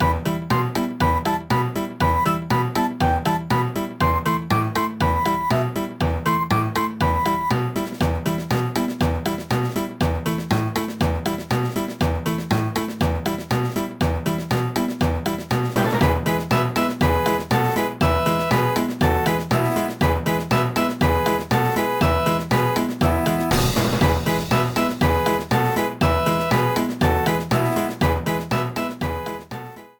Clipped to 30 seconds with fade-out.